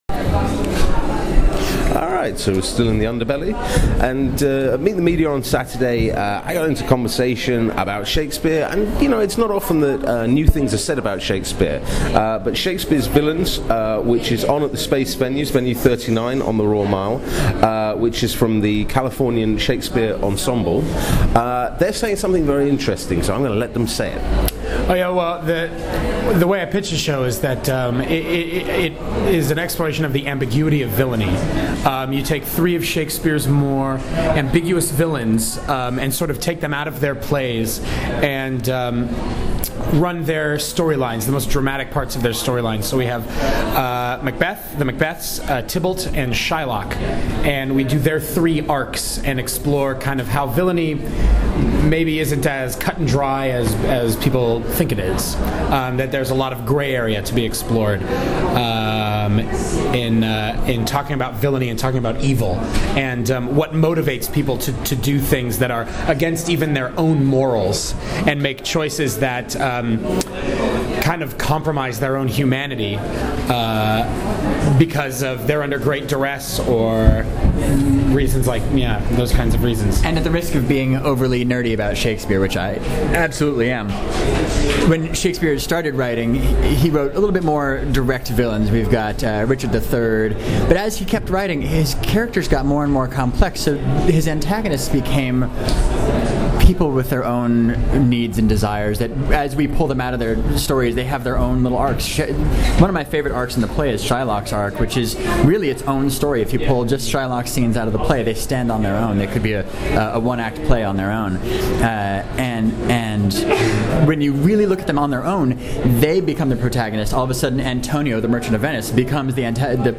Audio Interviews, Edinburgh Festivals 2014, Edinburgh Fringe 2014